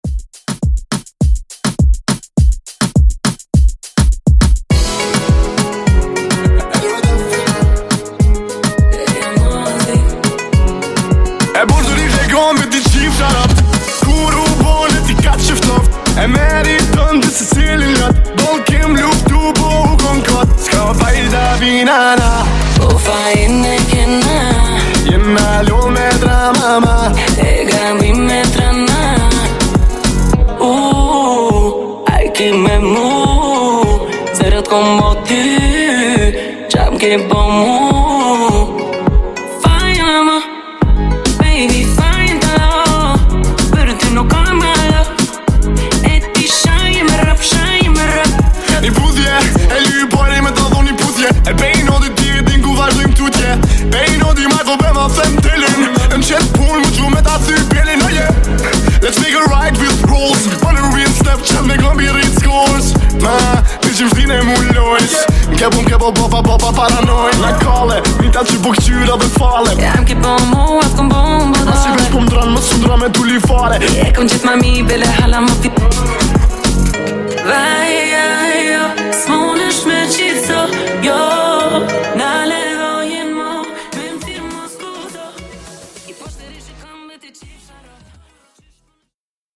Genre: 2000's
Clean BPM: 94 Time